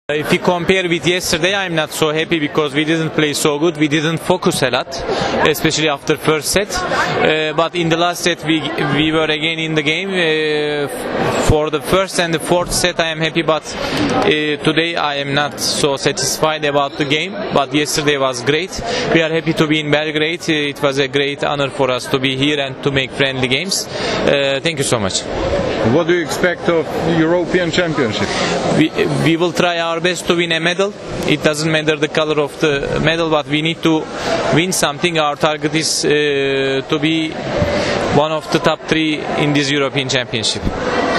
IZJAVA FERATA AKBAŠA